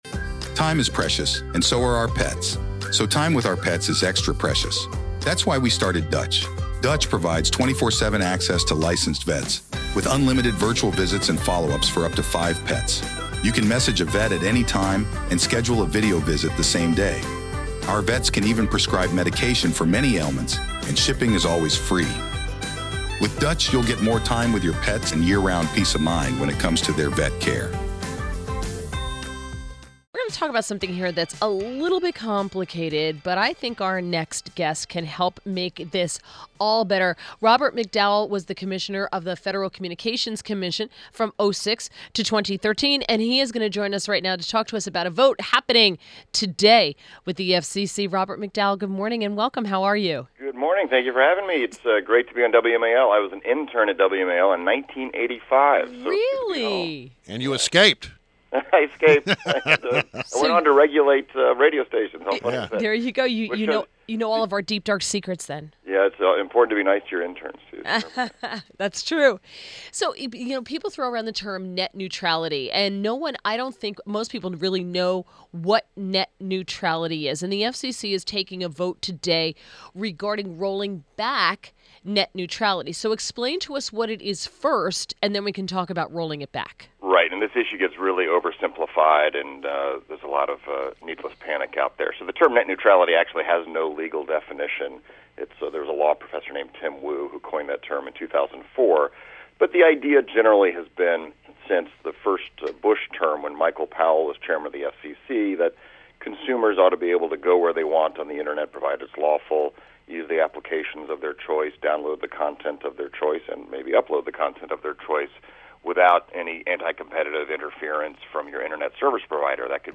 WMAL Interview - Robert McDowell 05.18.17